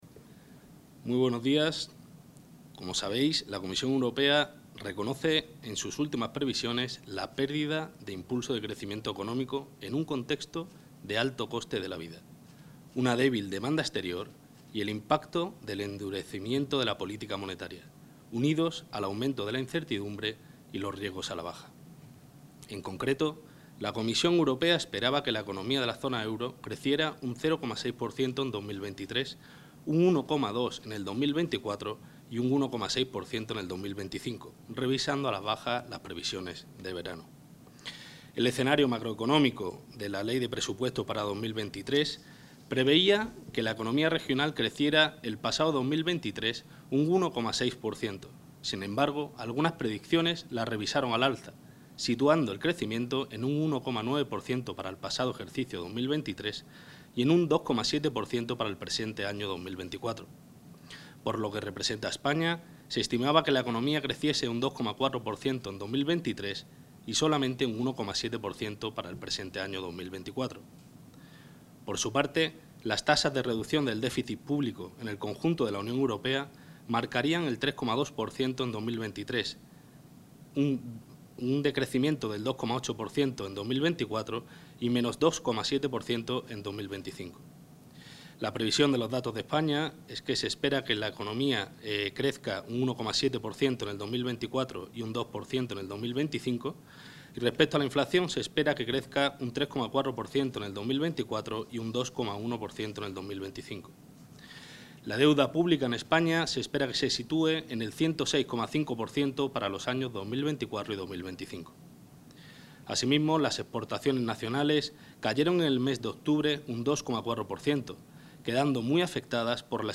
El gerente del Servicio Público de Empleo de Castilla y León (Ecyl), Álvaro Ramos-Catalina Ysasi, ha valorado hoy los datos de desempleo correspondientes al mes de diciembre de 2023.